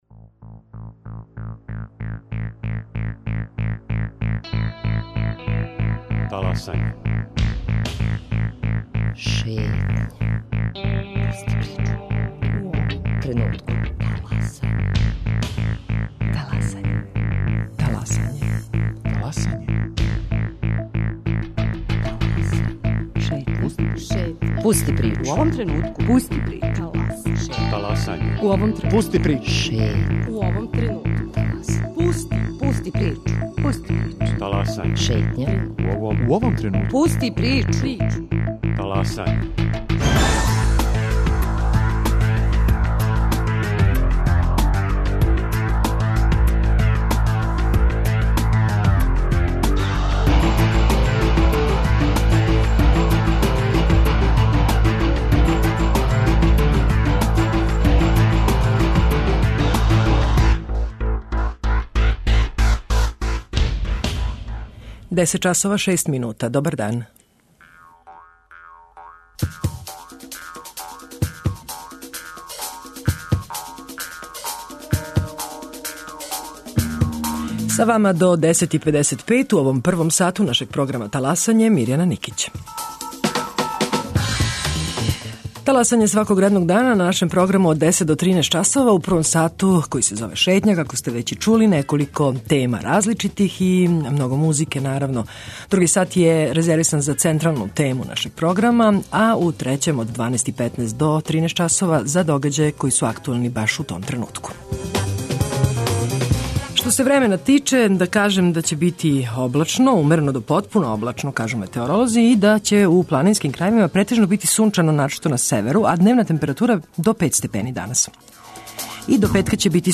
Публика ће моћи да их види до 12. децембра, а у Шетњи ћете чути разговор са уметником.